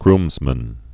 (grmzmən, grmz-)